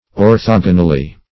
Search Result for " orthogonally" : The Collaborative International Dictionary of English v.0.48: Orthogonally \Or*thog"o*nal*ly\, adv.